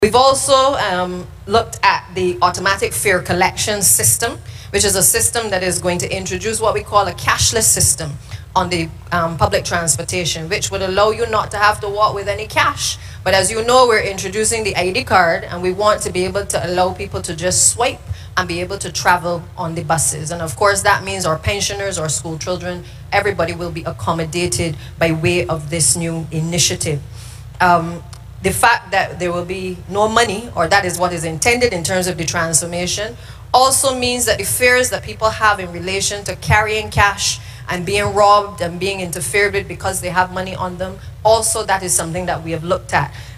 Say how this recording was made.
She spoke at the Transport Board’s 67th Anniversary service at the Collymore Rock Church of the Nazarene on Sunday.